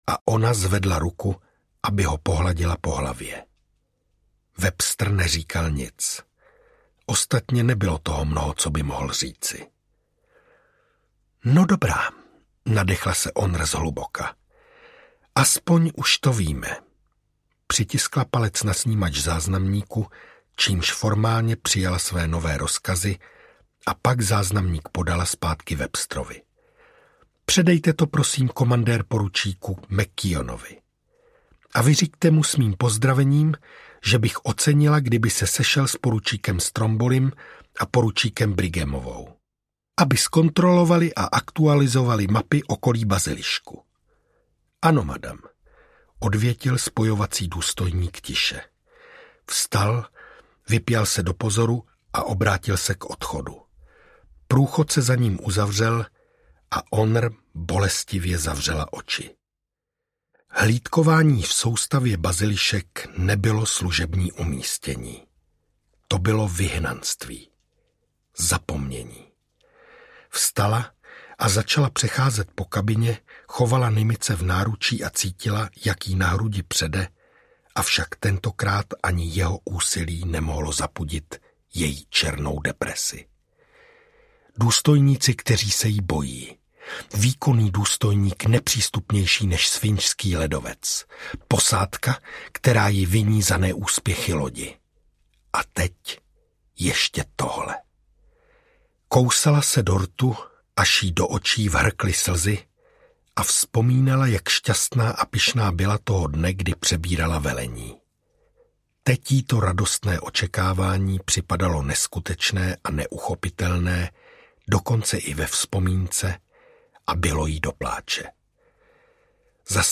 Audiokniha Stanice Bazilišek, kterou napsal David Weber. První díl nové řady válečné SF.
Ukázka z knihy
Vyrobilo studio Soundguru.